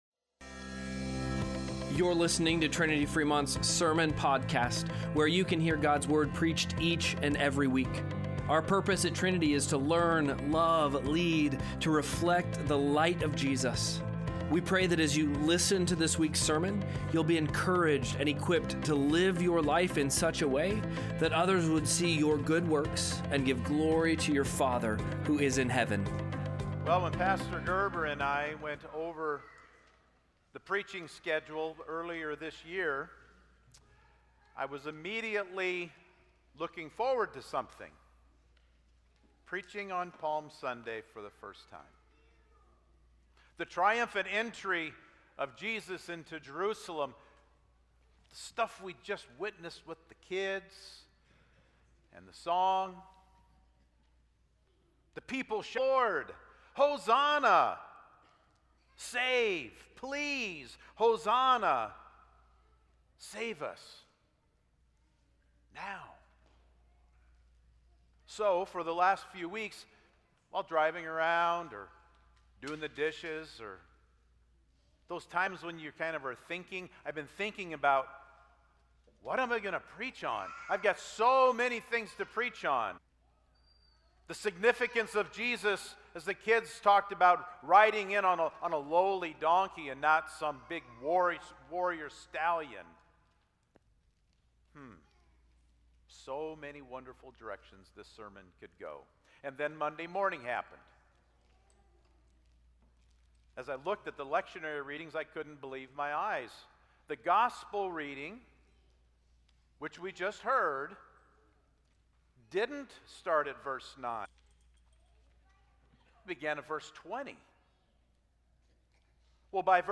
Sermont-Podcast-3-29.mp3